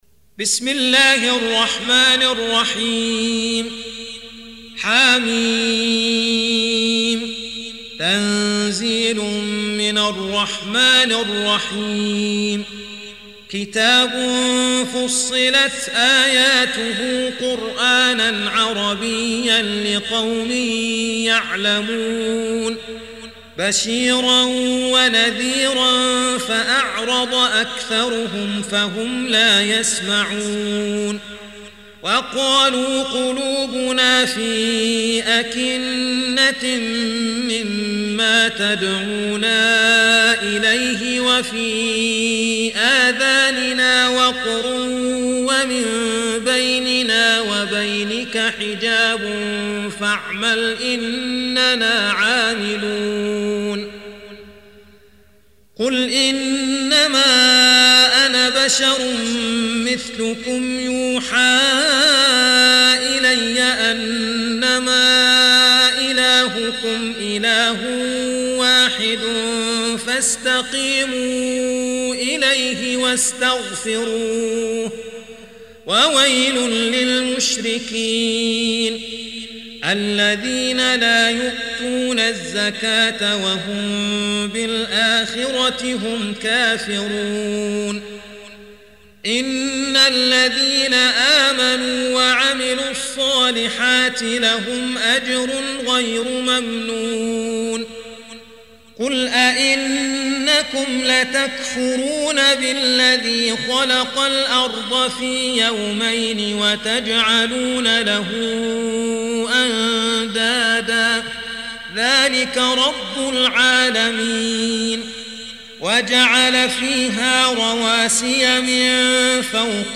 Surah Repeating تكرار السورة Download Surah حمّل السورة Reciting Murattalah Audio for 41. Surah Fussilat سورة فصّلت N.B *Surah Includes Al-Basmalah Reciters Sequents تتابع التلاوات Reciters Repeats تكرار التلاوات